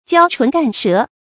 焦唇干舌 jiāo chún gàn shé
焦唇干舌发音
成语注音ㄐㄧㄠ ㄔㄨㄣˊ ㄍㄢ ㄕㄜˊ